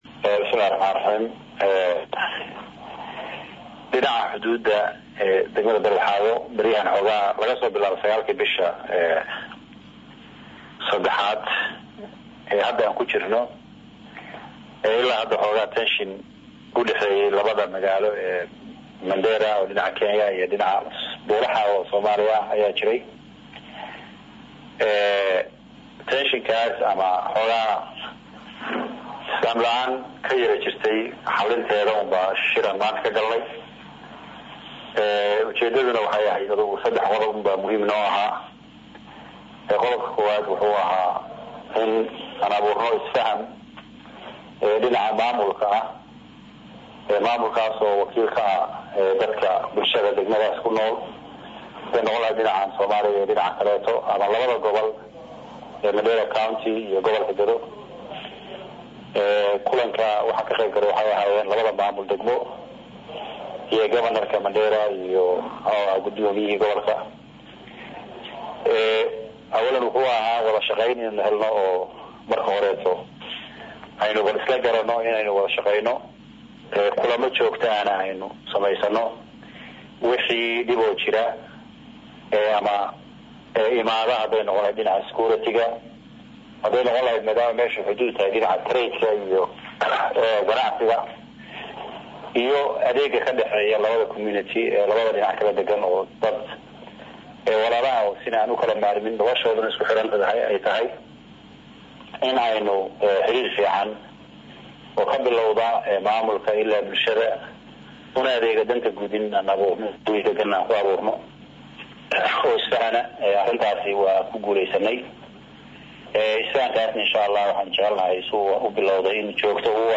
Mudane Maxamed Muxumud Maxamed Guddoomiyaha Gobolka Gedo wareysigaan uu siiyay Radio Muqdisho ayuu ku sheegay murankaan oo ahaa mid soo jiitamayay bilihii lasoo dhaafay hasa-yeeshee Maamulada magaalooyinka Mandheera iyo Beledxaawo ay dadaal xoogan ku bixiyeen sidii xal waara looga gaari lahaa muran xaduudeedka.